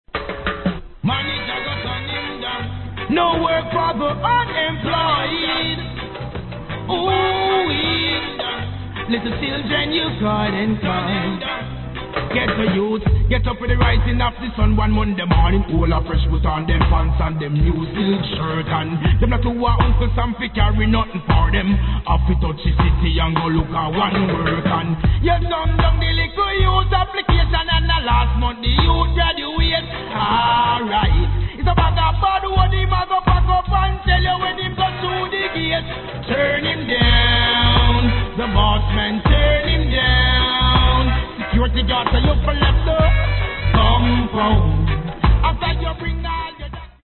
Please post only reggae discussions here
Wicked Riddim and a wicked chune too.